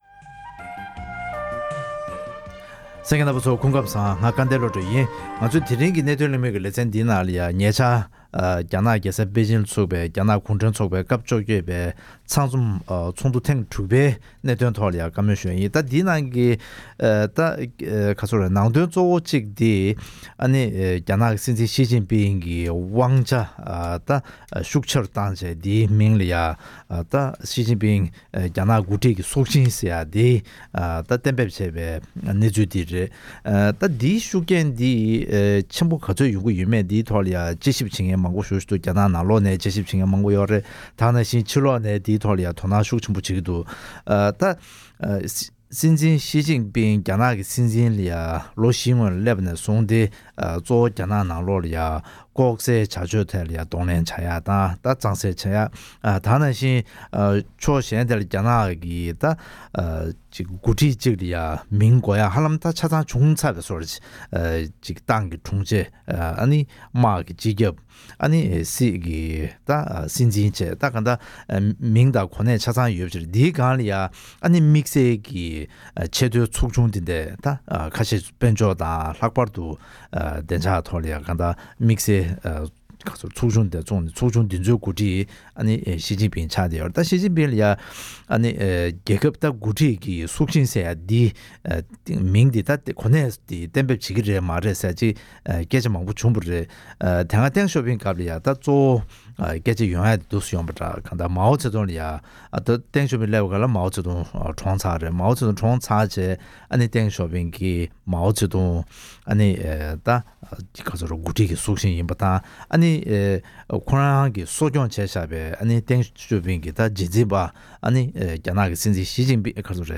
༄༅། །ཐེངས་འདིའི་གནད་དོན་གླེང་མོལ་གྱི་ལེ་ཚན་ནང་དུ། ཉེ་ཆར་རྒྱ་ནག་གི་རྒྱལ་ས་པེ་ཅིང་དུ་སྐོང་ཚོགས་བྱས་པའི་རྒྱ་ནག་གུང་ཁྲན་ཚོགས་པའི་ཚང་འཛོམས་ཚོགས་ཐེངས་དྲུག་པའི་ཐོག་རྒྱ་ནག་གི་སྲིད་འཛིན་ཞིའི་ཅིང་ཕིང་དམར་ཤོག་ཚོགས་པའི་སྲོག་ཤིང་རླབས་ཆེན་གྱི་འགོ་ཁྲིད་ཀྱི་མཚན་གནས་གཏན་འབེབས་བྱས་ཡོད་པ་སོགས་དང་འབྲེལ་བའི་སྐོར།